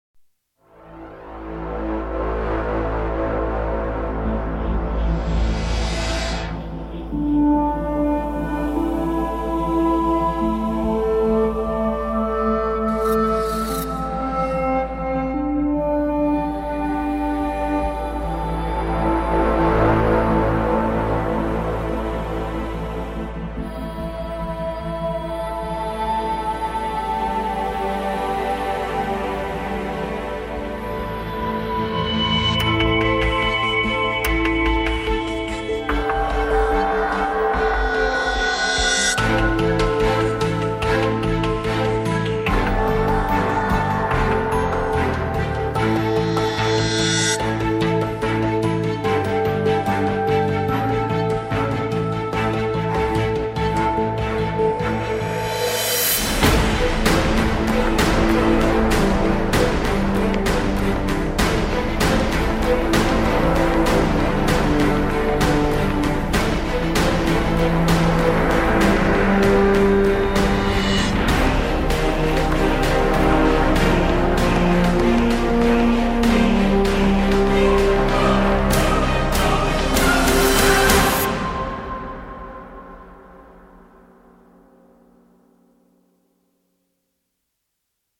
Epic track for trailers, RPG and 4X games.